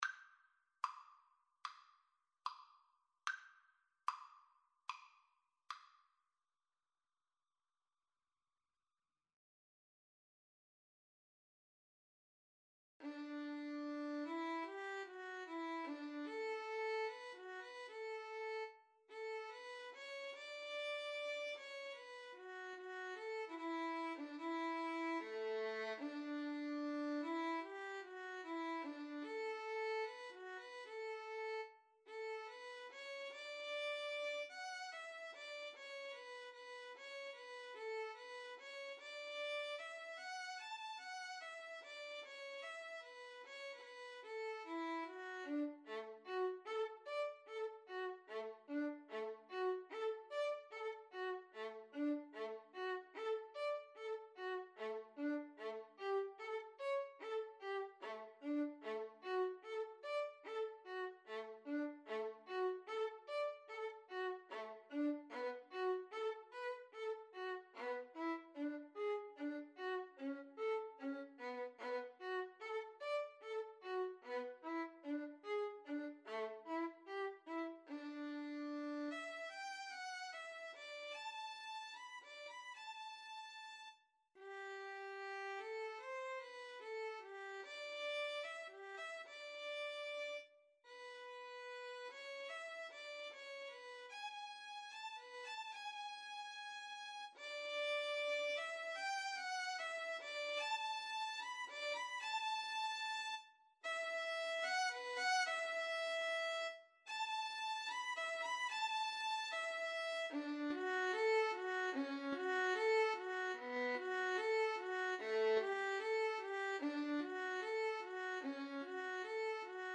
Classical (View more Classical Violin Duet Music)